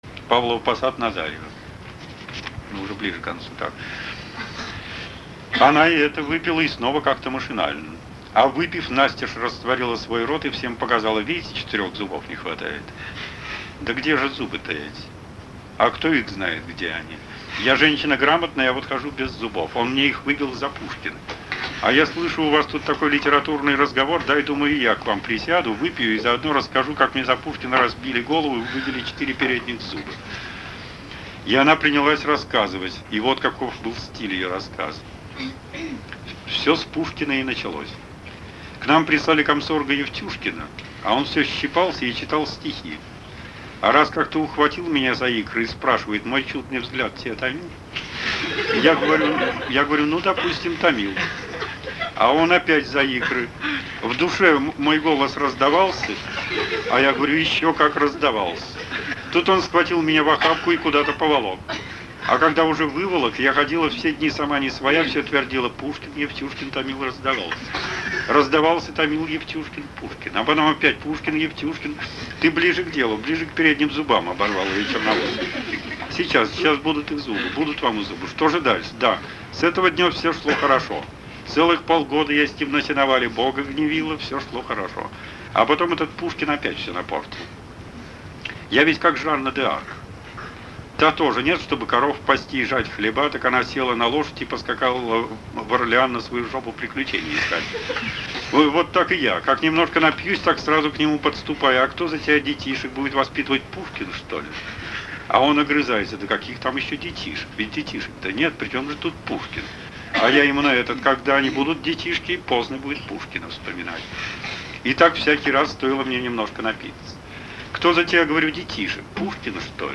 7. «Венедикт Ерофеев.”Москва – Петушки” – Павлово-Посад – Назарьево (читает автор)» /